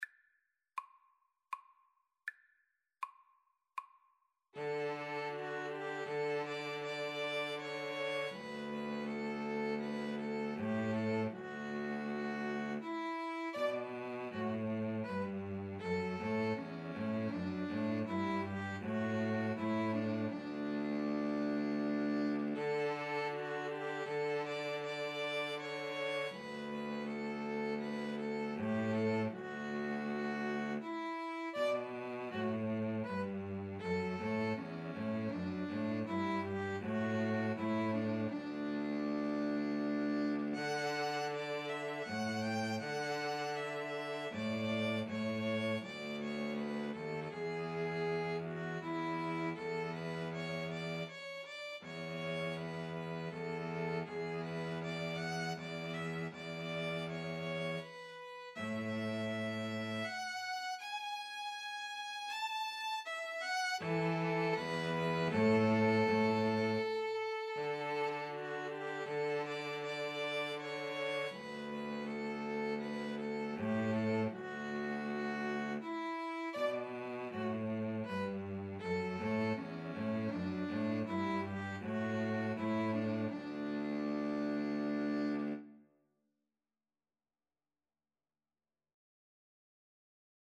D major (Sounding Pitch) (View more D major Music for String trio )
Andante
String trio  (View more Easy String trio Music)
Classical (View more Classical String trio Music)